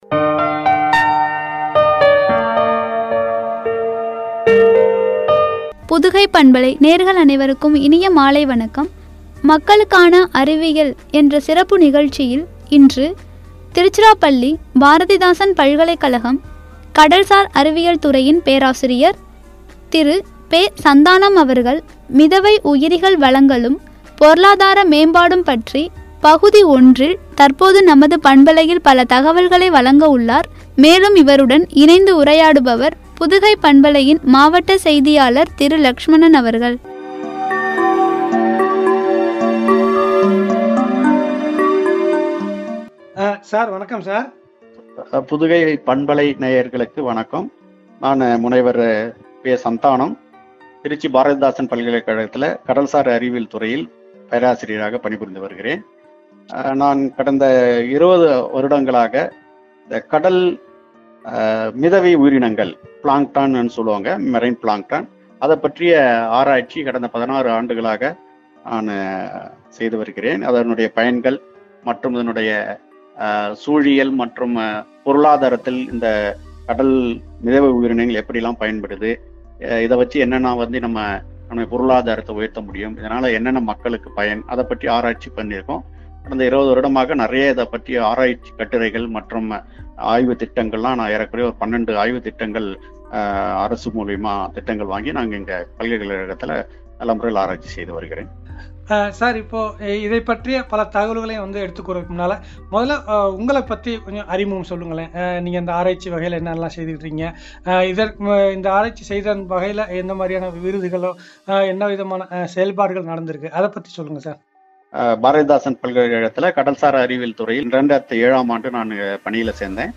என்ற தலைப்பில் வழங்கிய உரையாடல்.